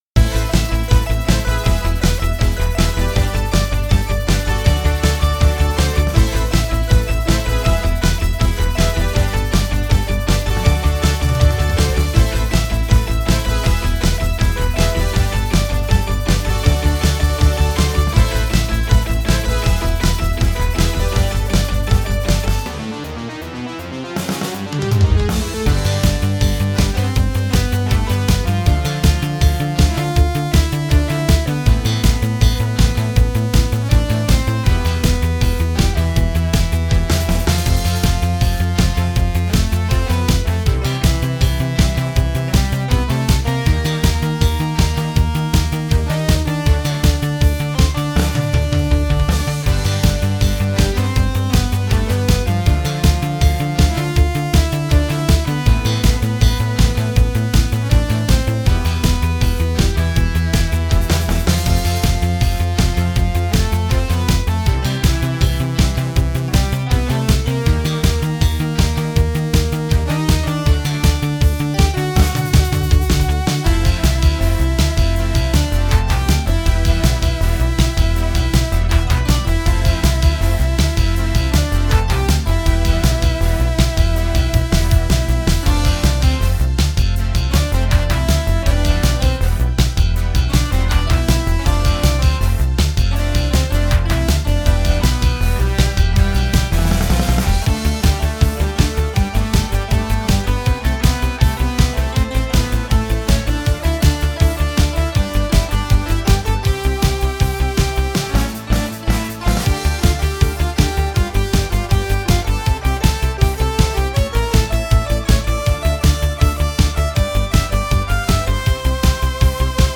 Video Game Music